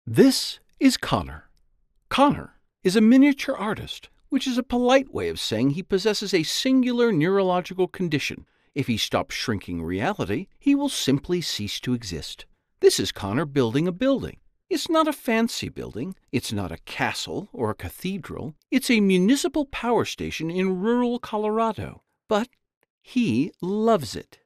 Adult (30-50) | Older Sound (50+)
0109Documentary_Voice_Over.mp3